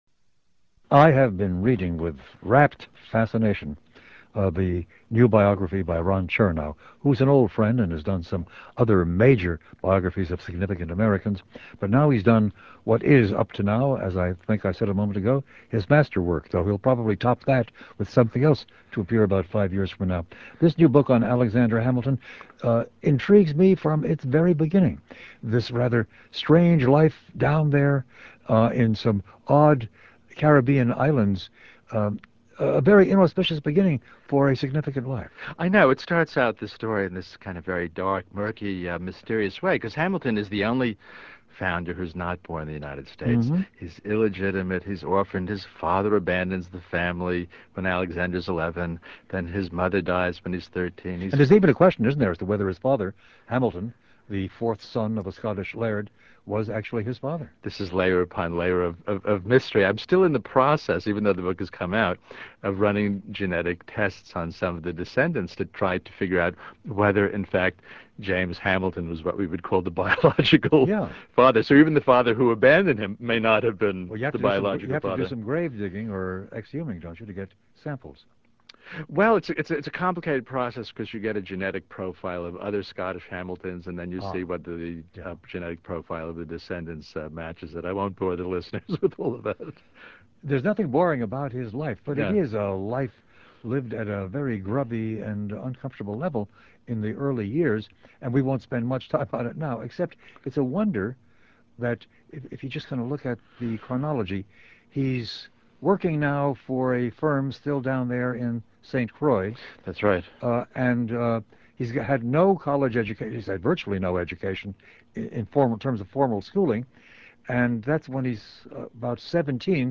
Ron Chernow, a great biographer, joins us in 2004 to discuss the astonishing life and achievement – and death in a duel – of the youngest of the founding fathers.